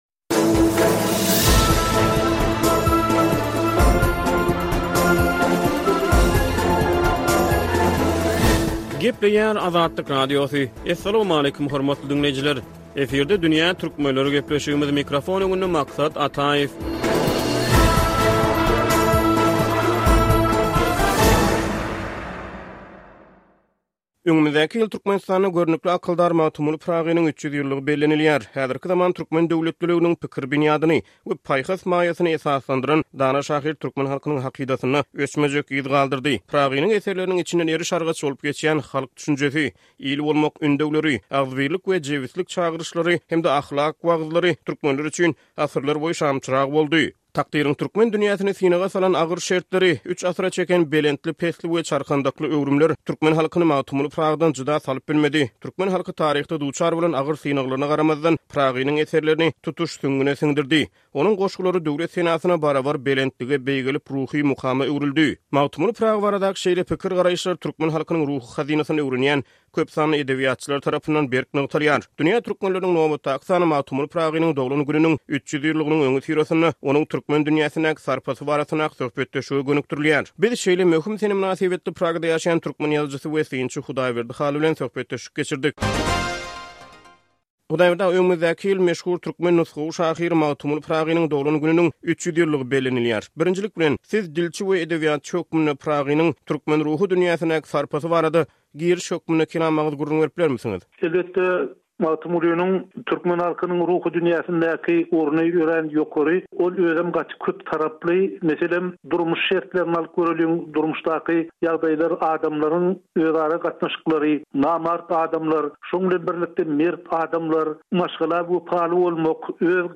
Dünýä Türkmenleriniň nobatdaky sany Magtymguly Pyragynyň doglan gününiň 300 ýyllygynyň öňüsyrasynda, onuň türkmen dünýäsindäki sarpasy barasyndaky söhbetdeşlige gönükdirilýär.